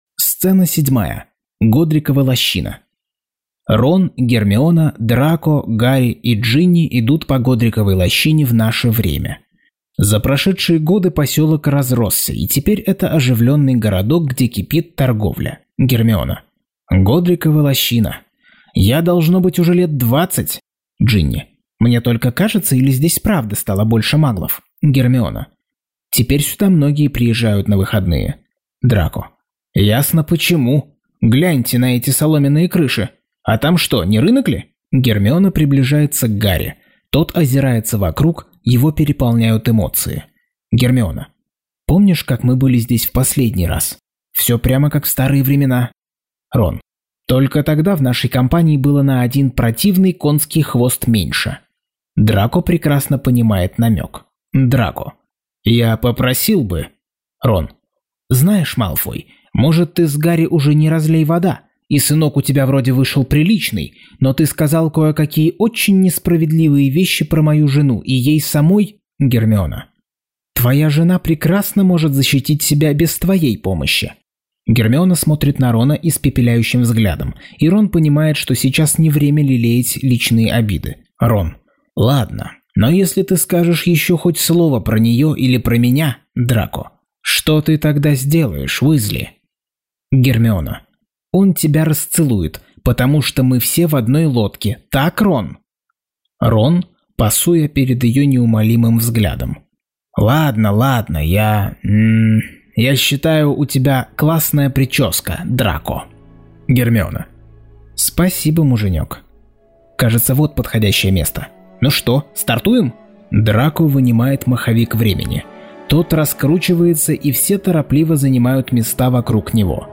Аудиокнига Гарри Поттер и проклятое дитя. Часть 59.